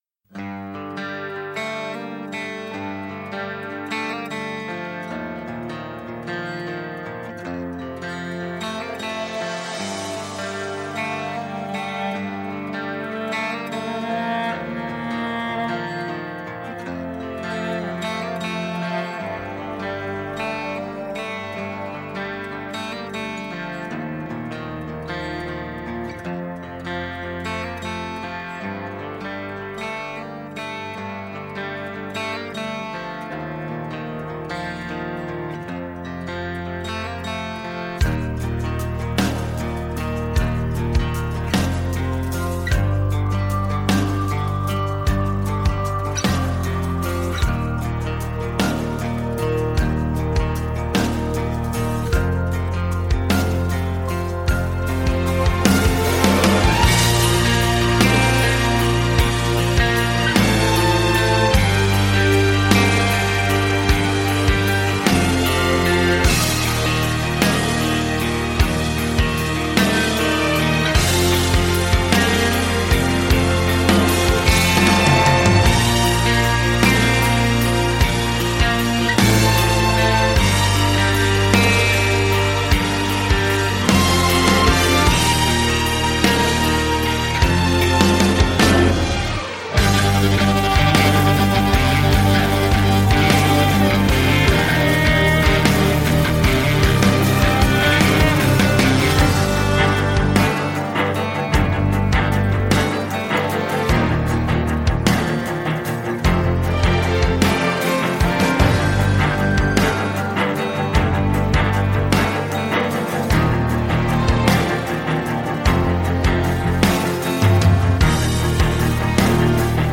Жанр: Hard Rock